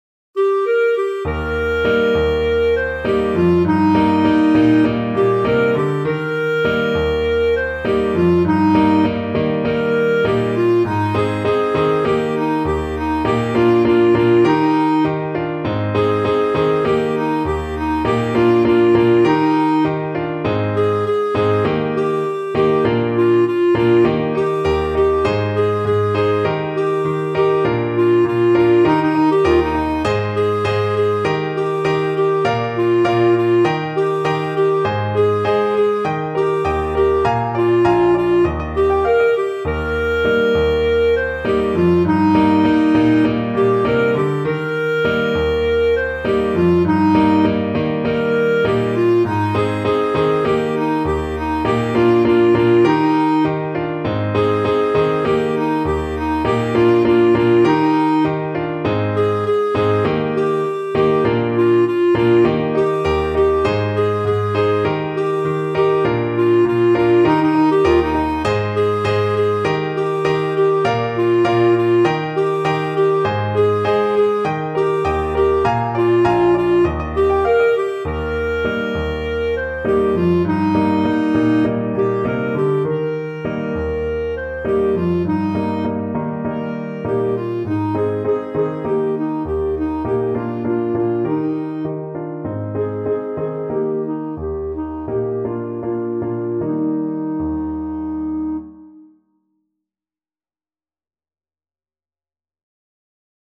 Clarinet
Eb major (Sounding Pitch) F major (Clarinet in Bb) (View more Eb major Music for Clarinet )
Joyfully =c.100
4/4 (View more 4/4 Music)
Traditional (View more Traditional Clarinet Music)
world (View more world Clarinet Music)